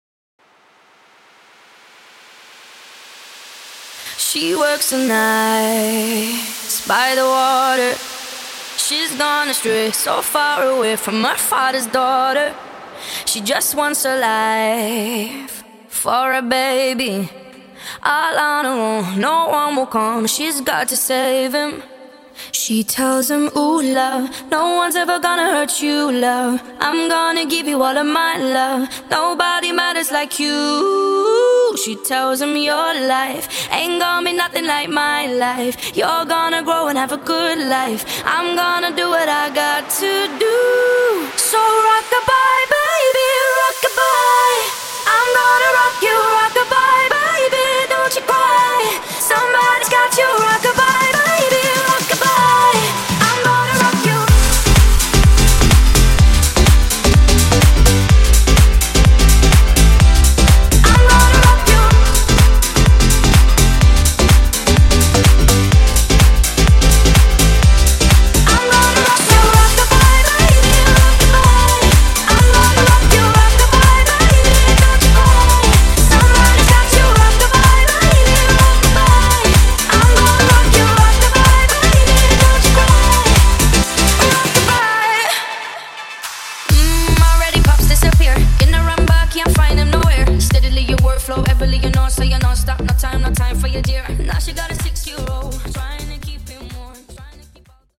Acapella Intros)Date Added